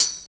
13 TAMB   -R.wav